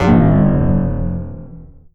SCIFI_Down_01_mono.wav